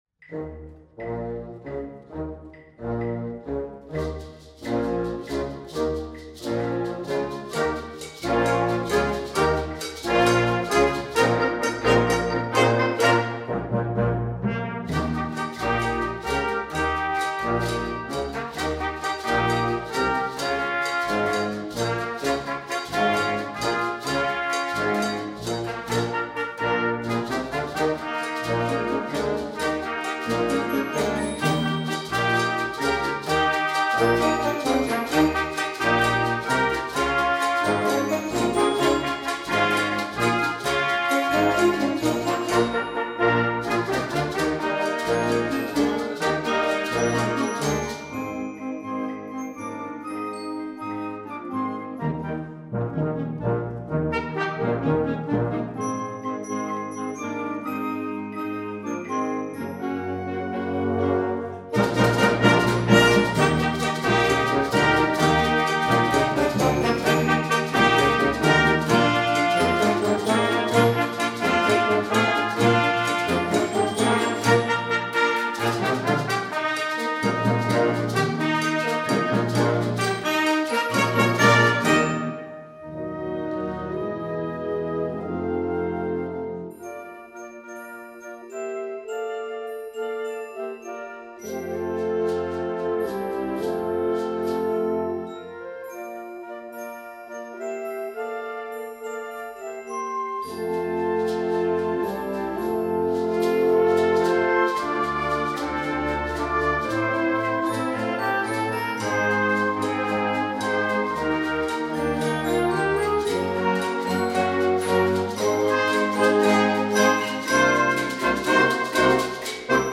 Voicing: Concert Band
Level: 1.5